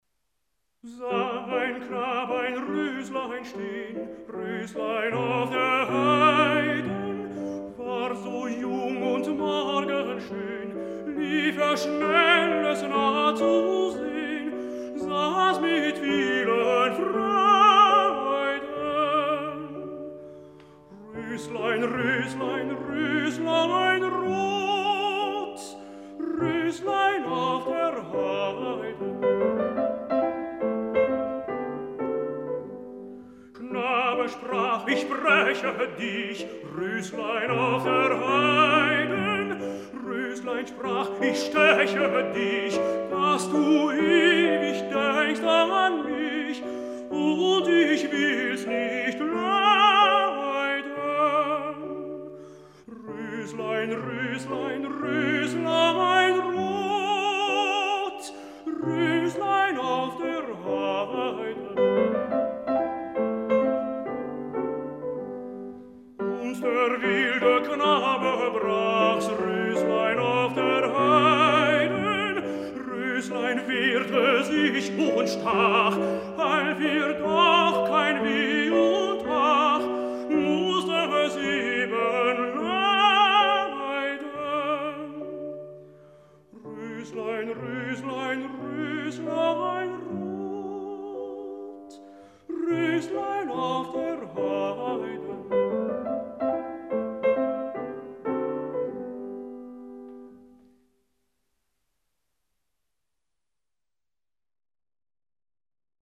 男高音
鋼琴
音樂類型：古典音樂